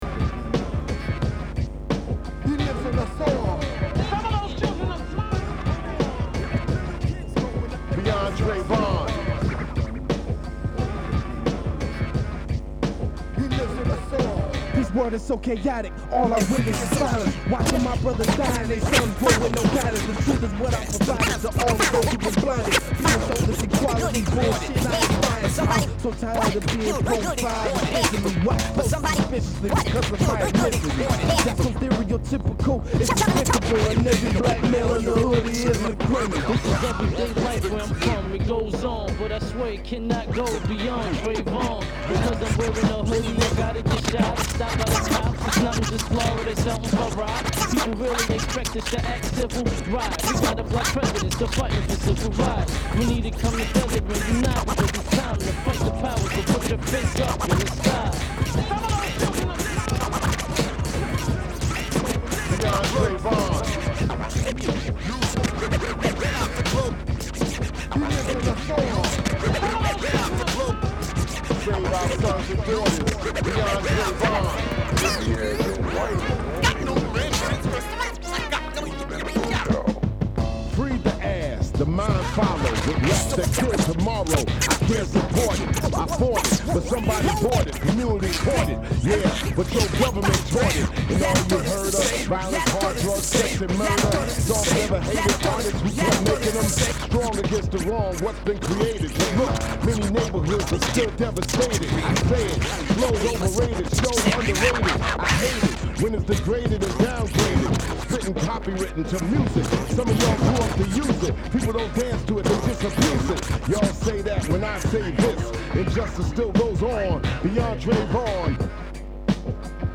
scratch remix